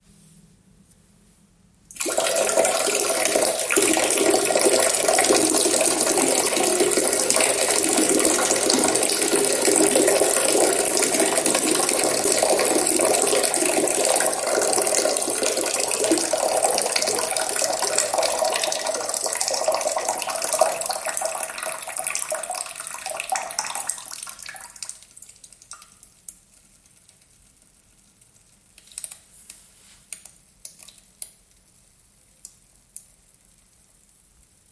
Riesco a percepire la schiumosità nell’audio, notevole gittata